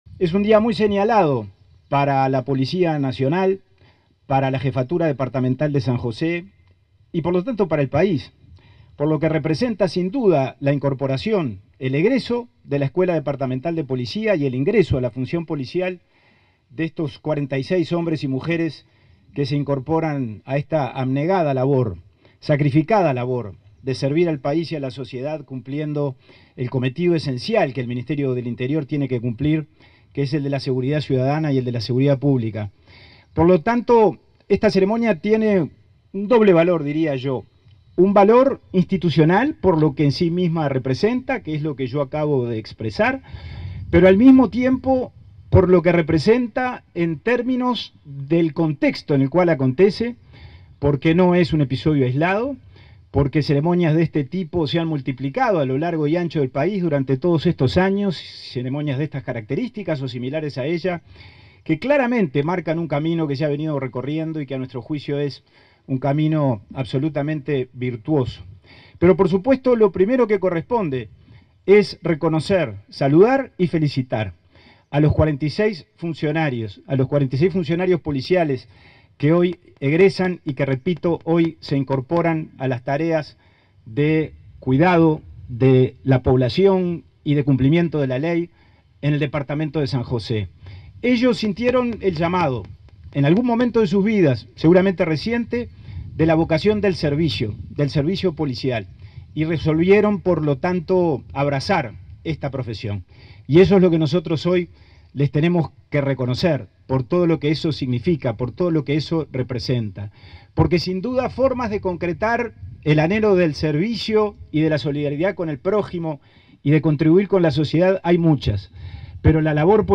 Palabras del ministro interino del Interior, Pablo Abdala
El ministro interino del Interior, Pablo Abdala, participó, este 23 de enero, en la ceremonia de egreso de 46 alumnos de la V Promoción Bicentenario